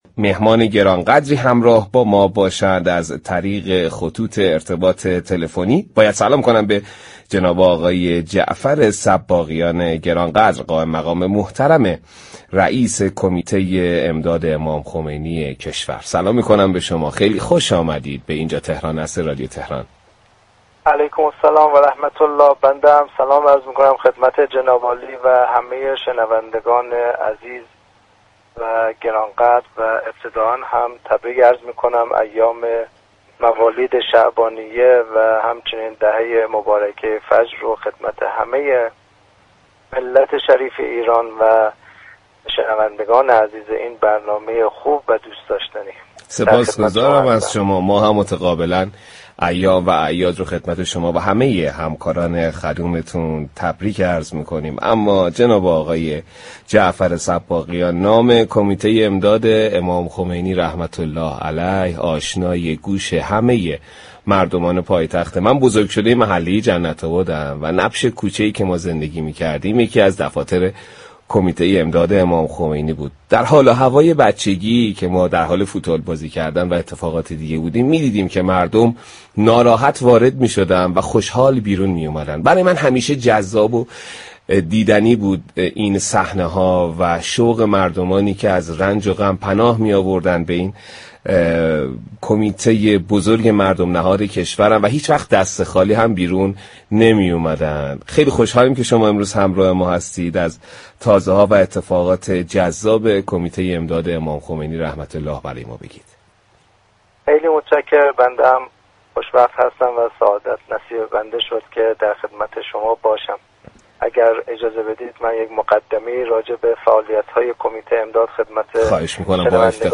كمیته امداد 20 روز پس از پیروزی انقلاب به دستور امام خمینی (ره) تاسیس شد به گزارش پایگاه اطلاع رسانی رادیو تهران؛ جعفر صباغیان قائم مقام كمیته امداد امام خمینی (ره) در گفت و گو با «اینجا تهران است» رادیو تهران اظهار داشت: كمیته امداد امام خمینی (ره) به دستور ایشان در تاریخ 14 اسفند 1357 و 20 روز پس از پیروزی انقلاب اسلامی تاسیس شد.